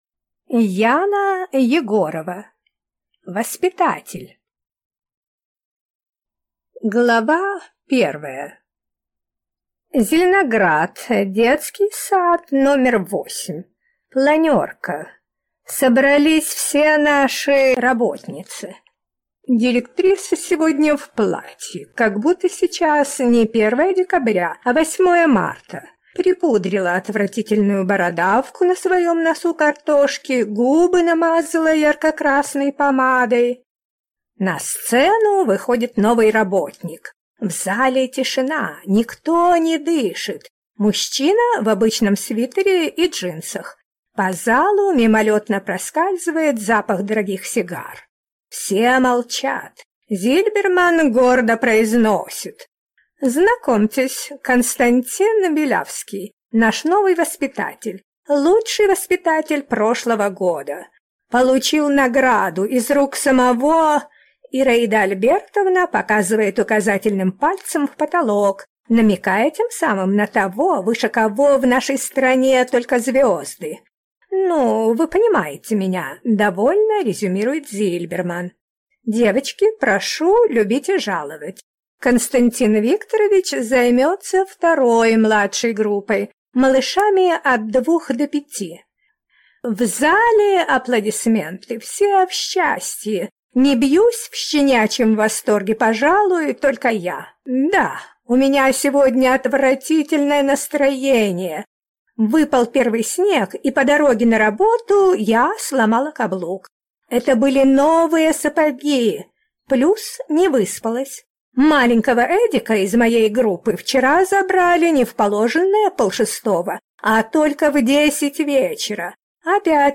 Аудиокнига Воспитатель | Библиотека аудиокниг